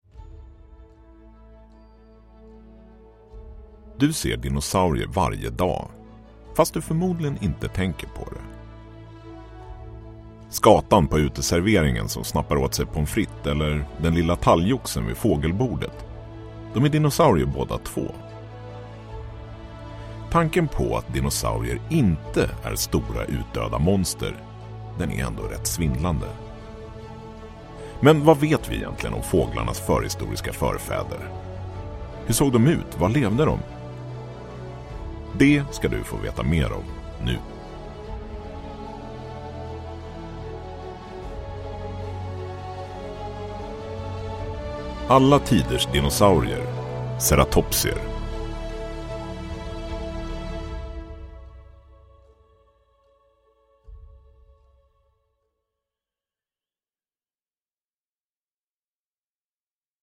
Alla tiders dinosaurier 2 - Ceratopsier – Ljudbok – Laddas ner